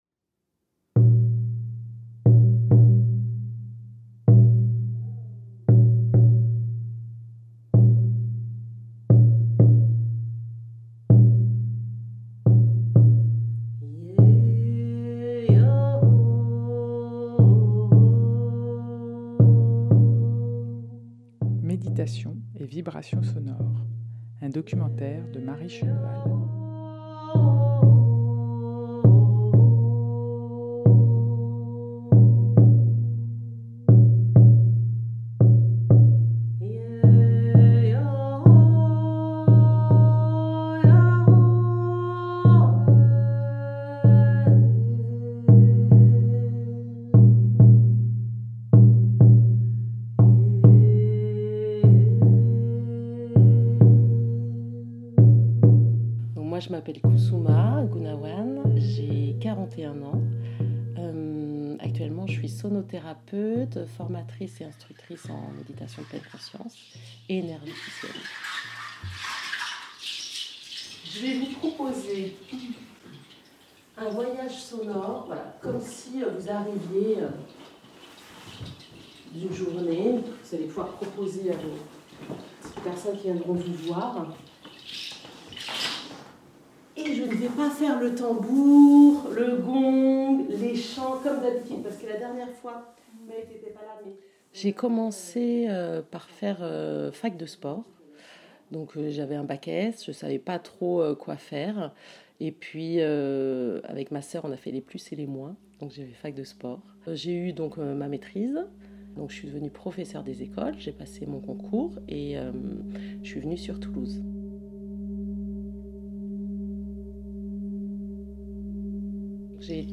Portrait sonore